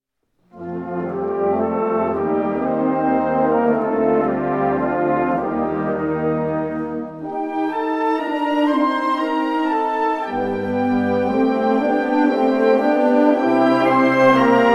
Bezetting Ha (harmonieorkest)